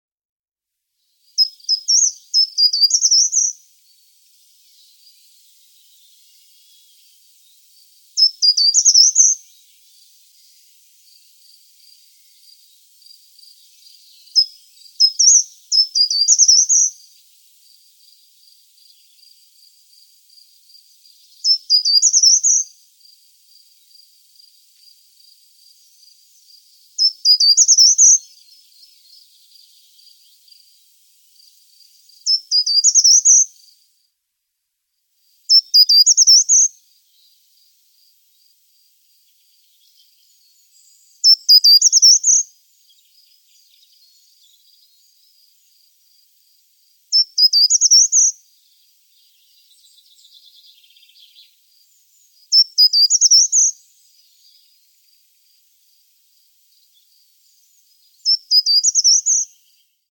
Waldbauml�ufer
Waldbauml�ufer [und H�rprobe: Gartenbauml�ufer (.mp3)]